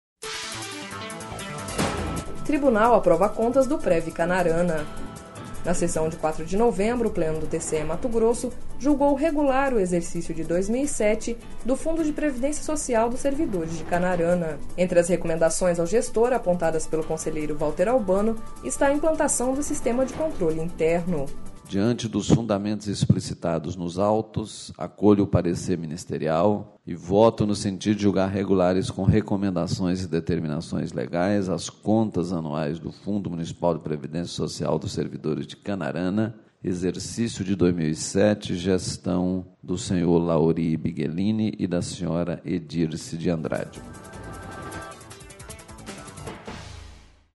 Na sessão de 04 de novembro, o Pleno do TCE-MT julgou regular o exercício de 2007 do Fundo de Previdência Social dos Servidores de Canarana./ Entre as recomendações ao gestor, apontadas pelo conselheiro Valter Albano, está a implantação do sistema de controle interno.// Sonora: Valter Albano – conselheiro do TCE-MT